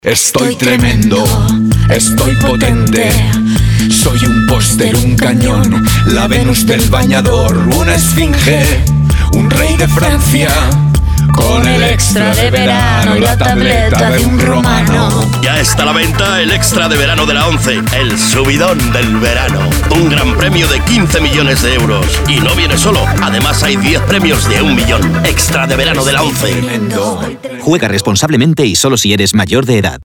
Cuña Extra de Verano de la ONCE (Romano) formato MP3 audio(1,15 MB)